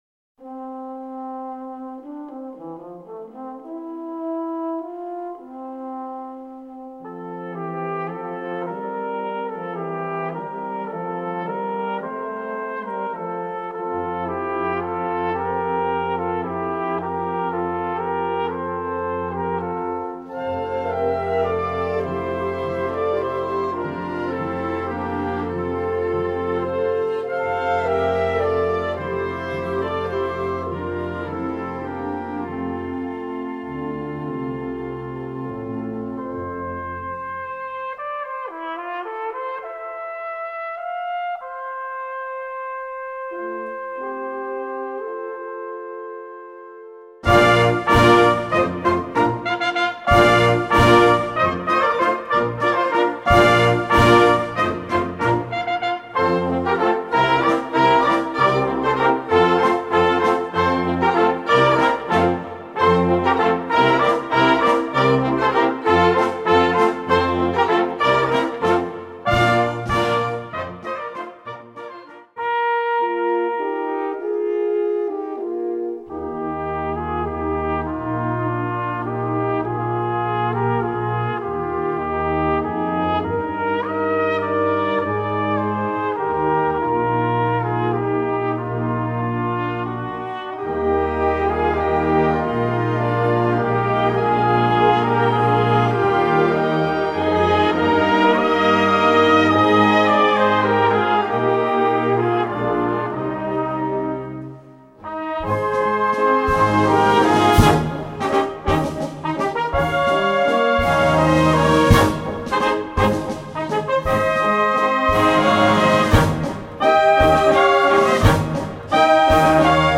Gattung: Konzertante Blasmusik
Besetzung: Blasorchester
Das Stück schließt mit einem rassigen Galopp.